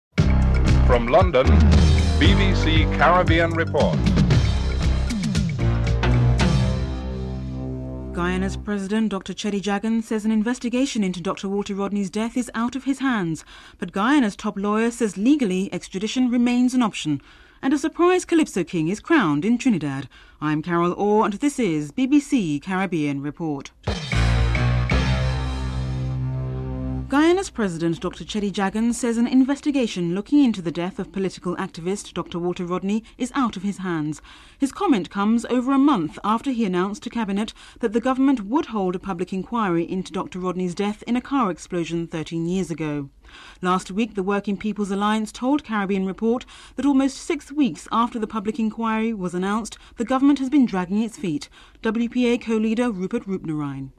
7. Wrap up and theme music (14:43-15:18)